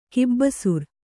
♪ kibbasur